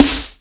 • Tight Urban Steel Snare Drum Sample F# Key 66.wav
Royality free snare drum sample tuned to the F# note. Loudest frequency: 1545Hz
tight-urban-steel-snare-drum-sample-f-sharp-key-66-RNb.wav